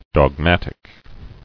[dog·mat·ic]